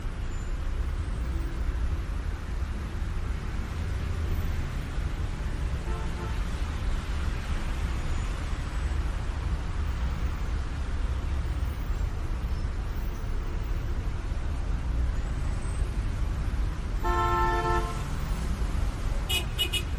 Free Ambient sound effect: Market Crowd.
Market Crowd
399_market_crowd.mp3